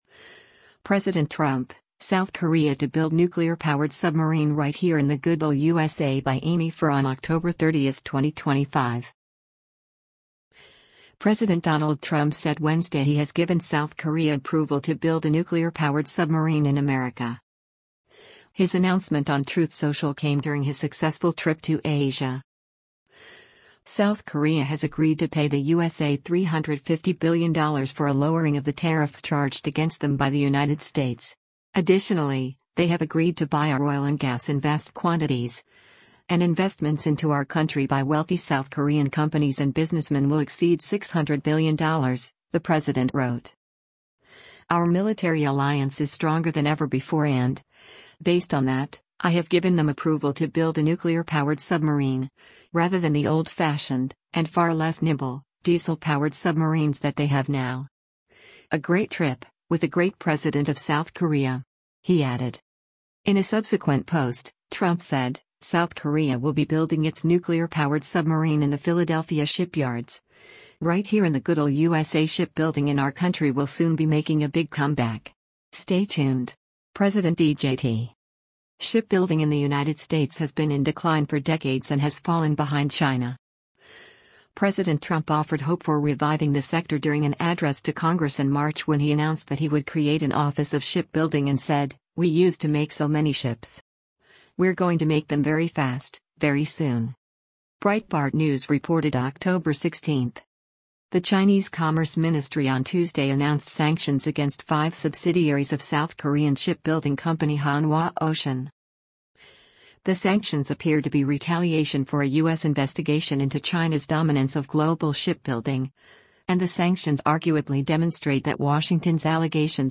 WATCH — President Trump Delivers Remarks in South Korea: